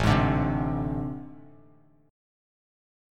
AmM7bb5 chord